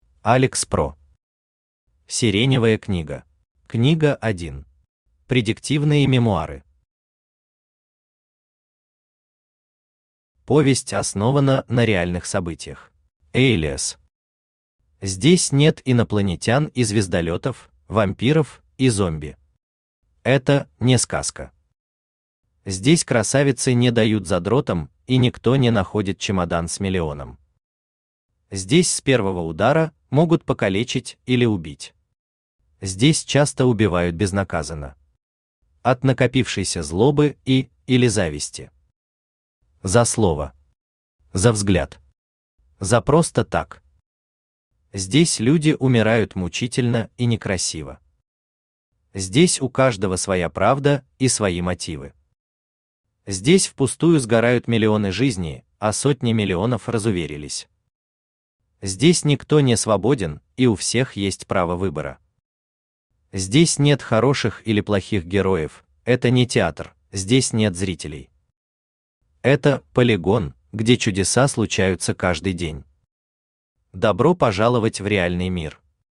Аудиокнига Сиреневая книга | Библиотека аудиокниг
Aудиокнига Сиреневая книга Автор Alex PRO Читает аудиокнигу Авточтец ЛитРес.